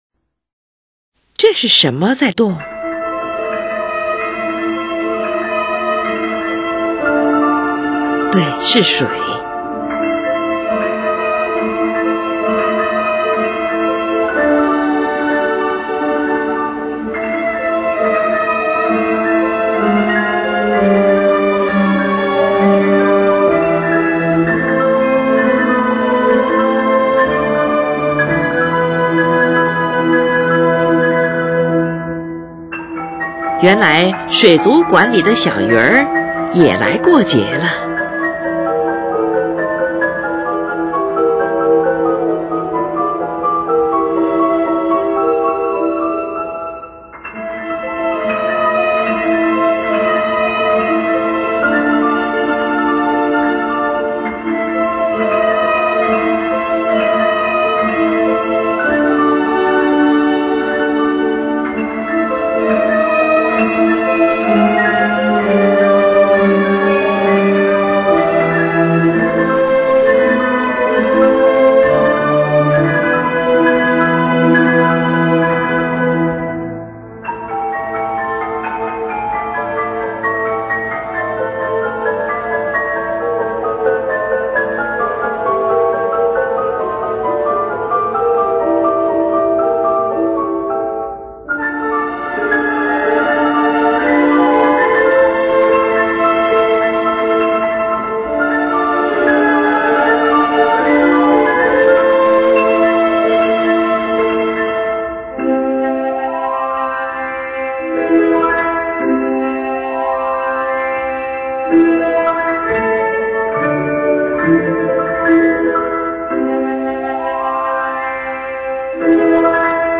作者采用小提琴、长笛和玻璃琴键共同表现出十分圆润、流动的音响效果，使听众宛如置身于水晶宫一般。